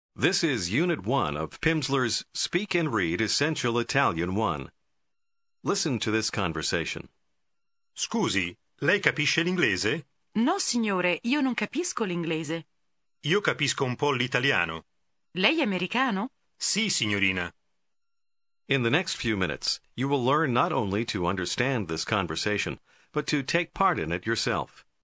Аудио курс для самостоятельного изучения итальянского языка.